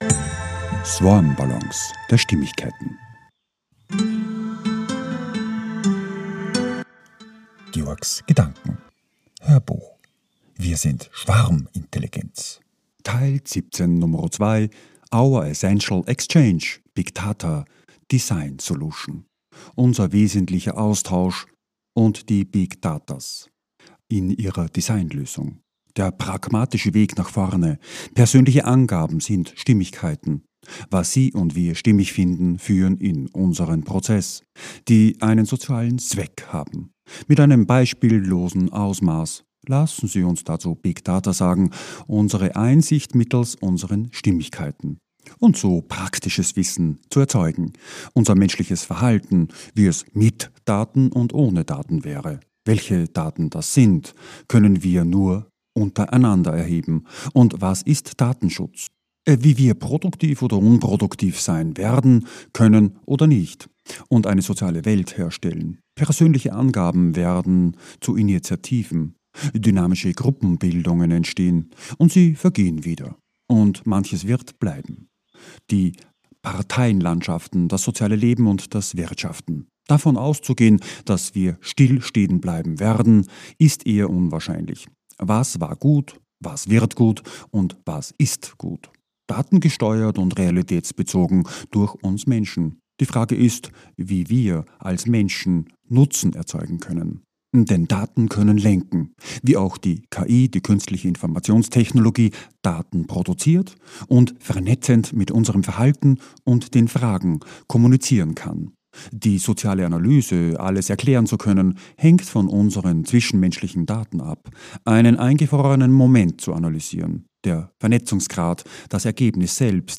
HÖRBUCH - 017.2 - WIR SIND SCHWARMINTELLIGENZ - Our essential Exchange - big DATA Design Solution